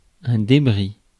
Ääntäminen
IPA : /dɪˈtraɪtəs/